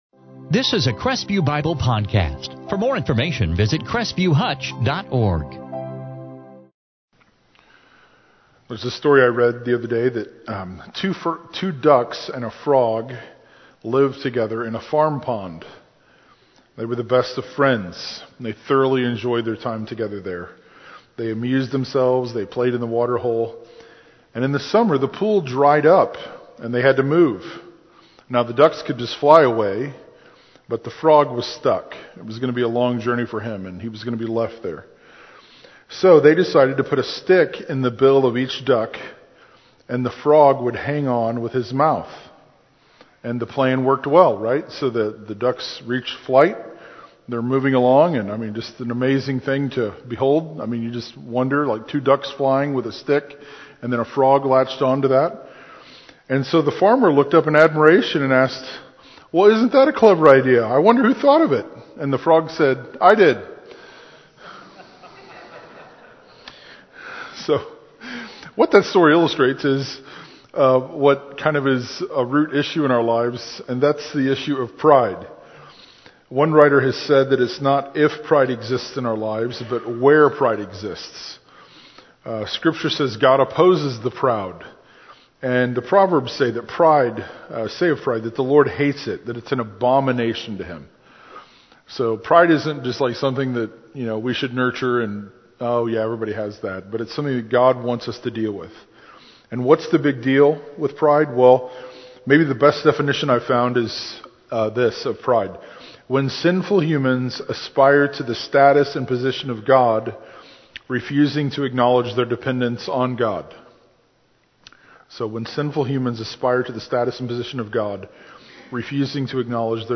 In this sermon from Luke 1:39-56, we see how humility is possible with God as we’re closely connected to His work and praising Him.